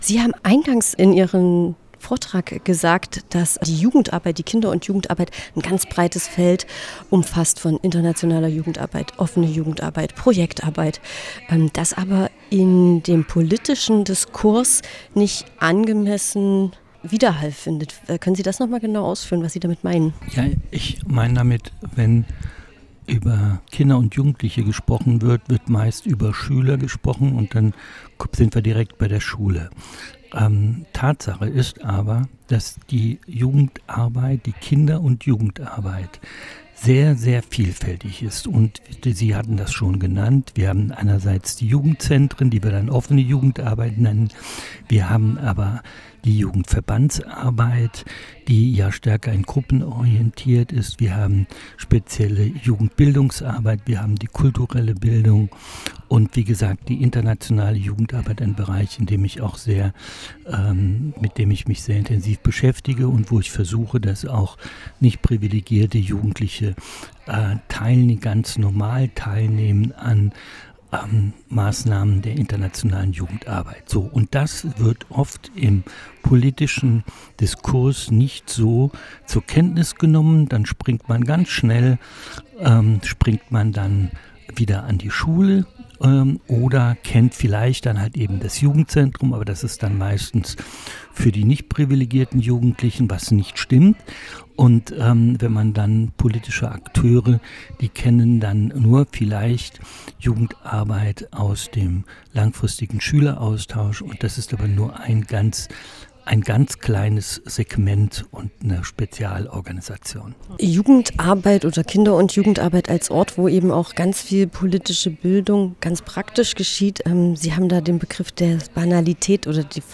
Fachtag "Demokratie erlebbar machen" | Interviews zum Nachhören